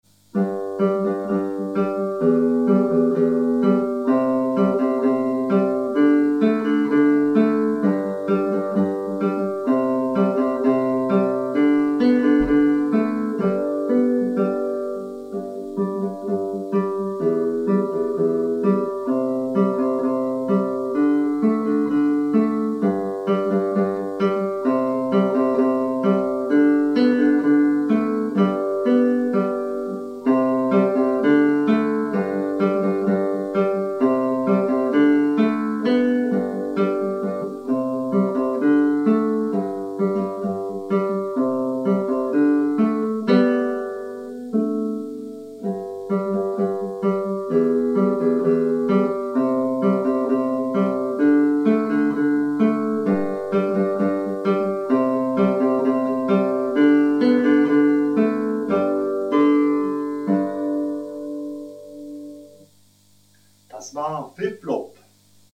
18 kleine Gitarrenstücke